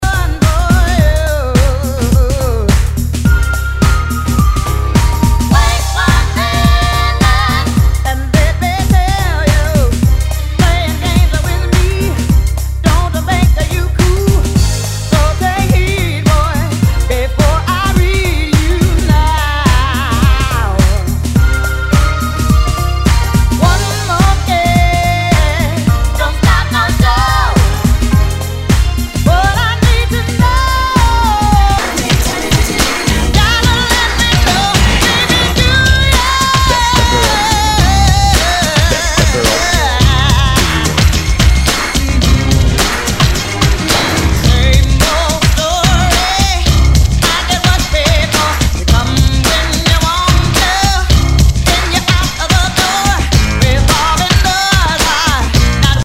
HOUSE/TECHNO/ELECTRO
ナイス！ヴォーカル・ハウス / R&B・クラシック！